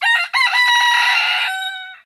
rooster-1.ogg